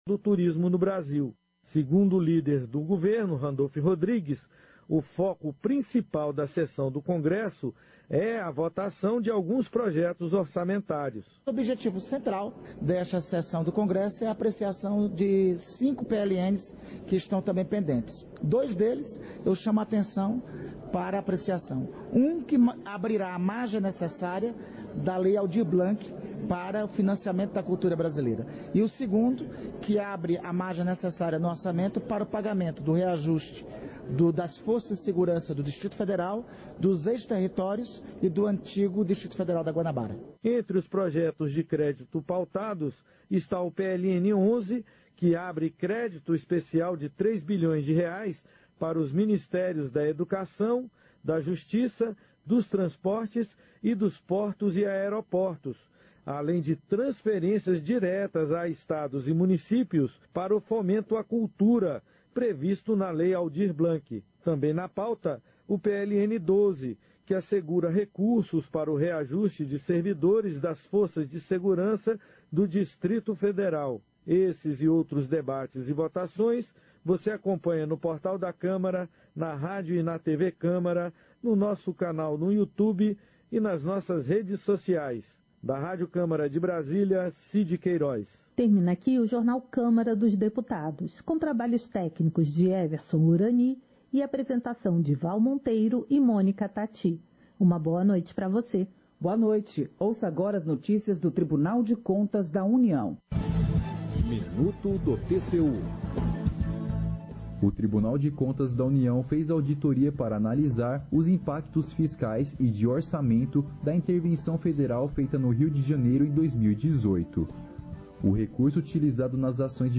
Sessão Ordinária 21/2023